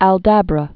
(ăl-dăbrə)